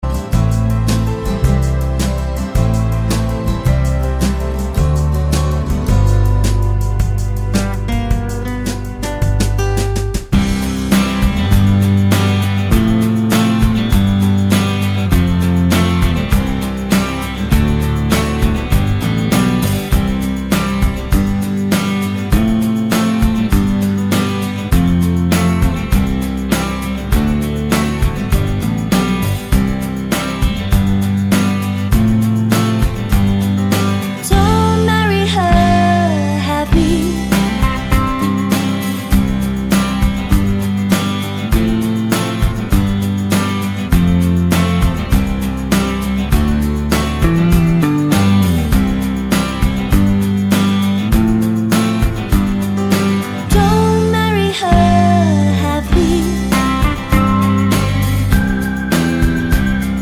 Female Songs Pop (1990s) 3:37 Buy £1.50